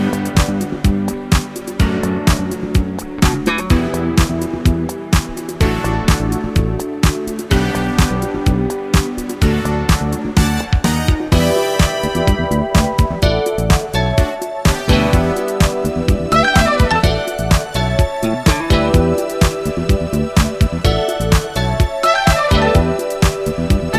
Minus Sax Pop (1980s) 3:24 Buy £1.50